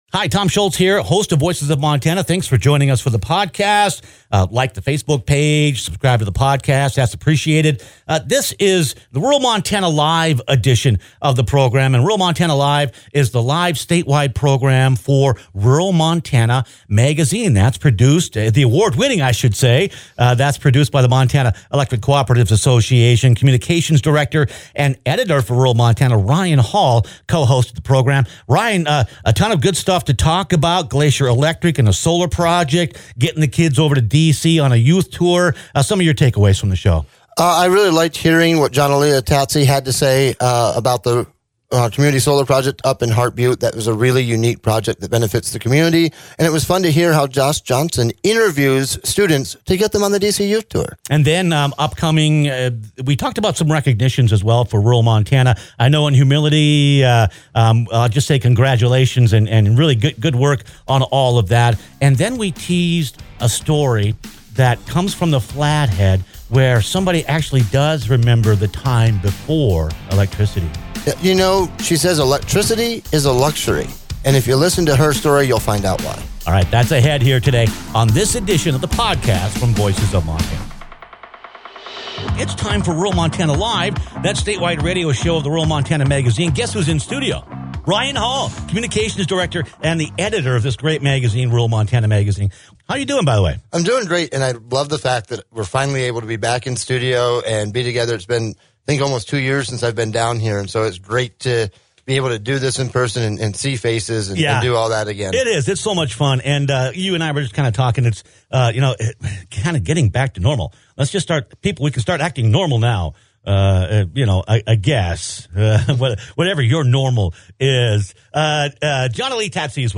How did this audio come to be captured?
In this edition of Rural Montana LIVE, the statewide radio show of Rural Montana Magazine published by the Montana Electric Cooperatives' Association